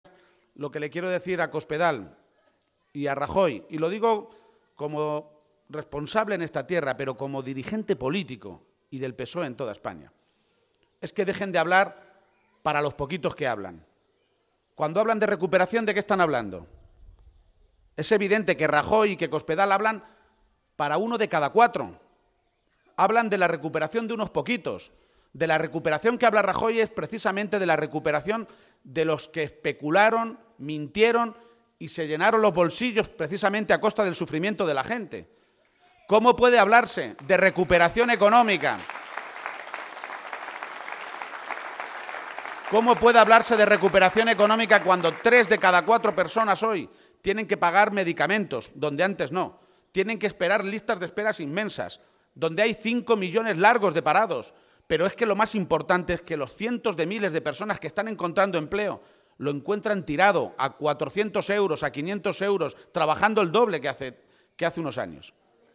García-Page, que ha participado esta mañana en un acto público con militantes y simpatizantes socialistas en Villatobas (Toledo) ha pedido a Rajoy y Cospedal “que dejen de mentir y de tomar por tontos a los españoles porque cuando hablan de recuperación están hablando solo para uno de cada cuatro ciudadanos de este país, hablan de la recuperación de unos pocos, de aquellos que precisamente especularon y se llenaron los bolsillos a costa de la mayoría de la gente”.